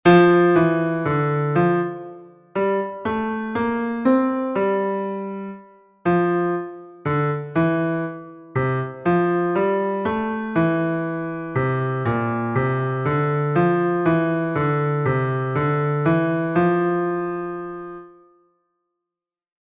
keeping the beat exercise 6